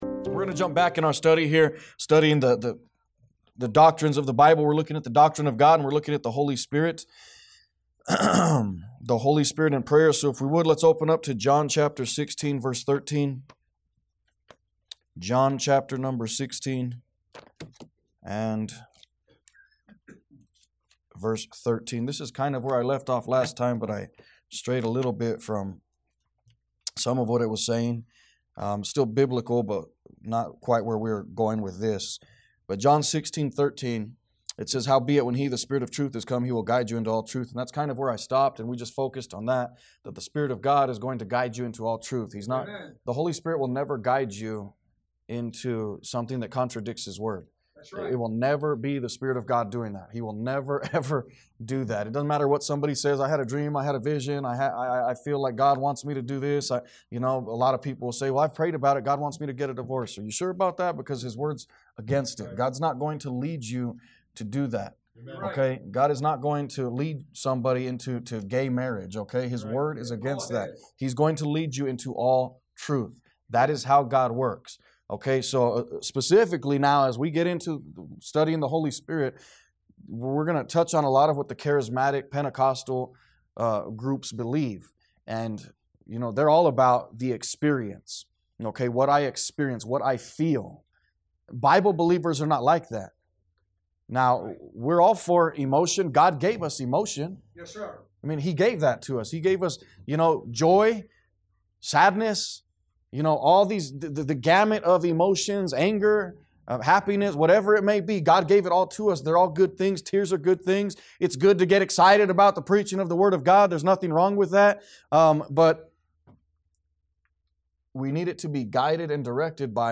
A message from the series "What is a Disciple?."